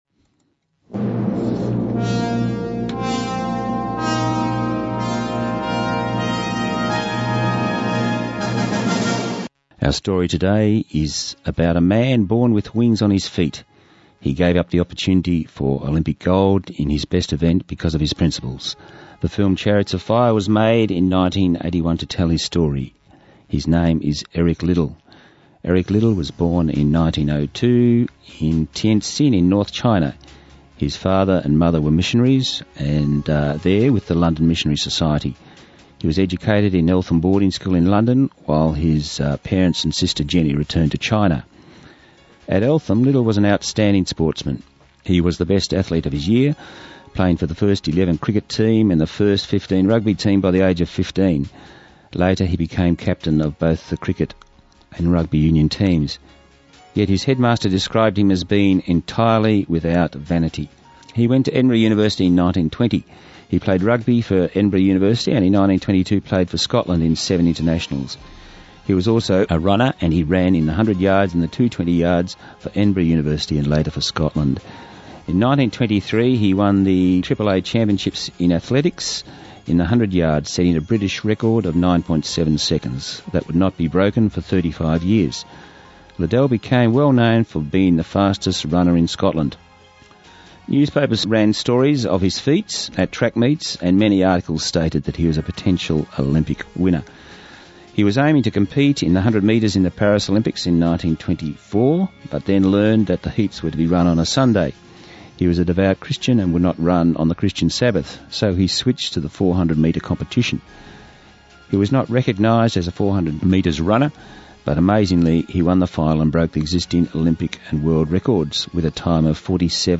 Hear the story of Eric Liddell, the olympian who would not race on the Christian sabbath – a Sunday. Broadcast on Southern FM 88.3 on the Songs of Hope program.